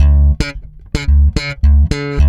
-AL DISCO D#.wav